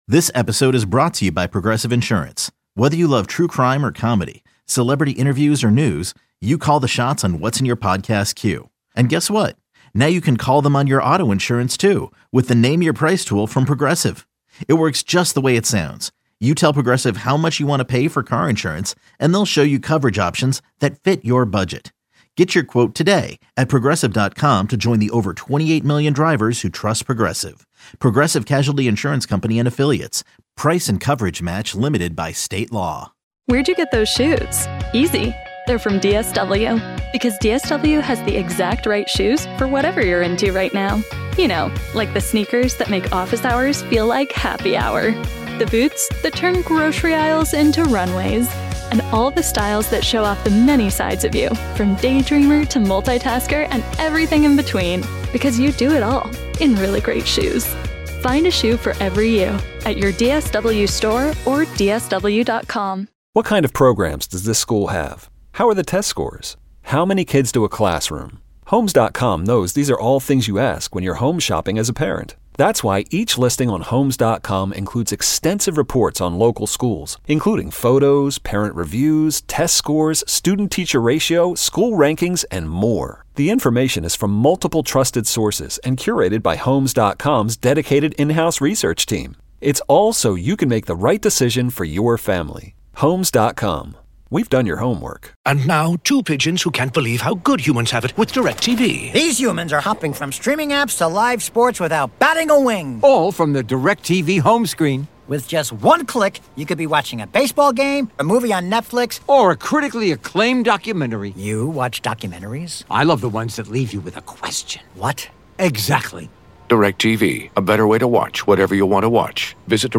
fun, smart and compelling Chicago sports talk with great listener interaction. The show features discussion of the Bears, Blackhawks, Bulls, Cubs and White Sox as well as the biggest sports headlines beyond Chicago.
Recurring guests include Bears linebacker T.J. Edwards, Pro Football Talk founder Mike Florio, Cubs outfielder Ian Happ and Cubs president of baseball operations Jed Hoyer.
Catch the show live Monday through Friday (10 a.m.- 2 p.m. CT) on 670 The Score, the exclusive audio home of the Cubs and the Bulls, or on the Audacy app.